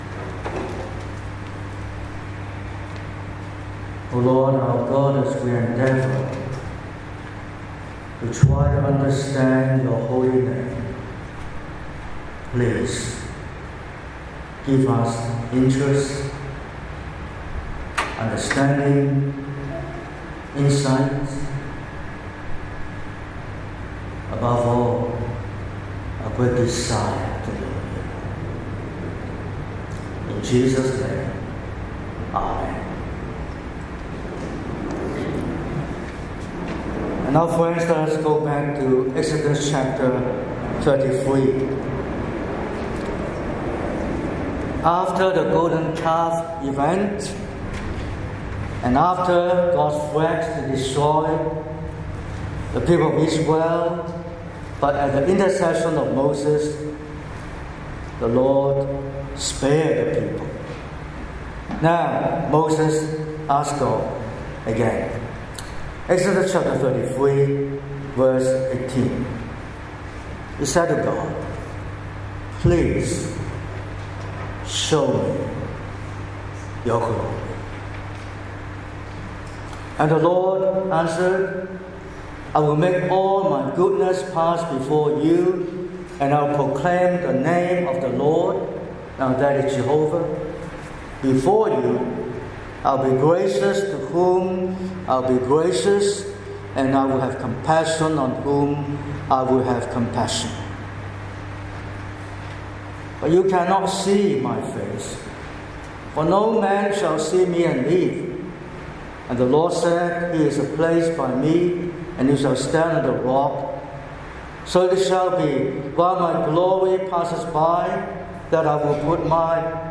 Weekly sermons from Maroubra Presbyterian Church